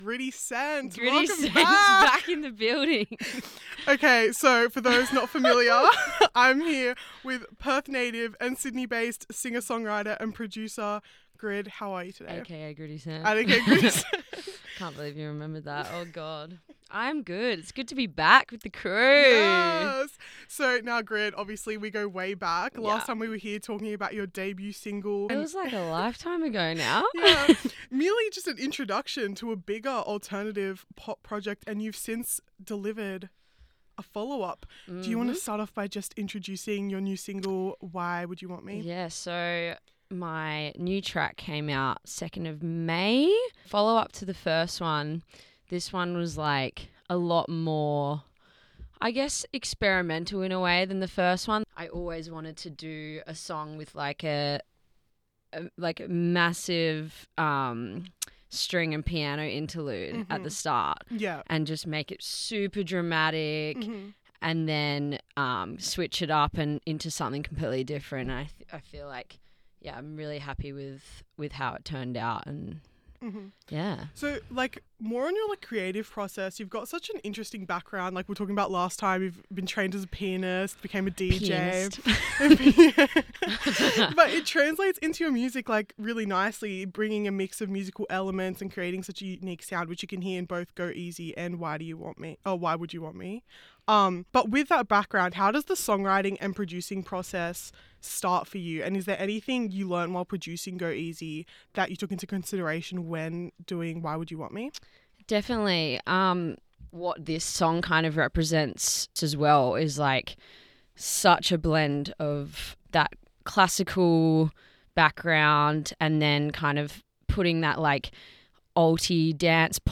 Grid-Interview-2-WWYWM-FINAL.mp3